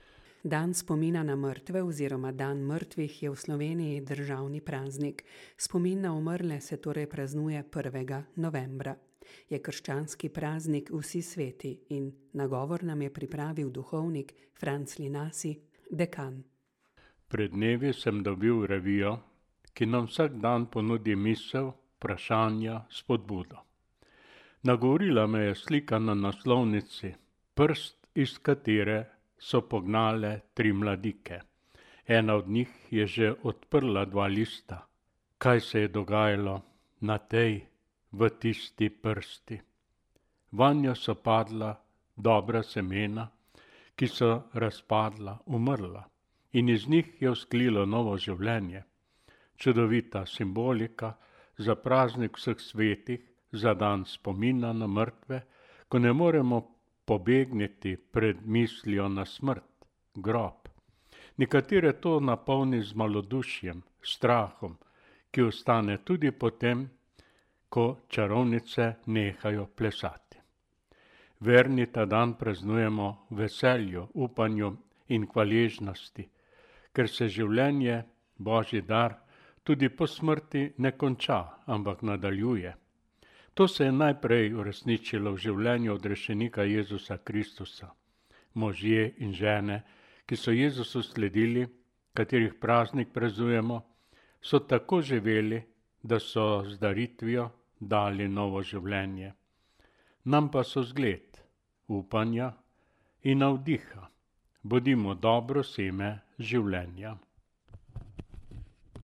nagovor